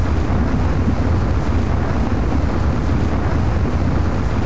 thrust.wav